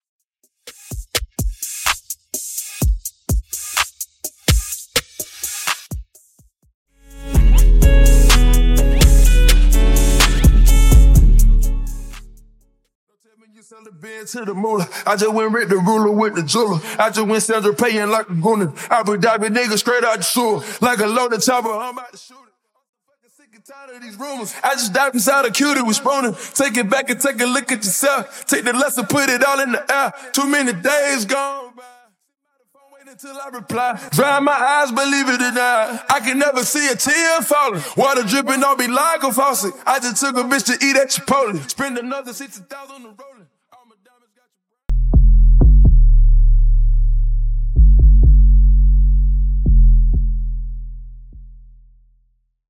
808 & Bassline Stem
Percussion, Drums & Subwoofer Stem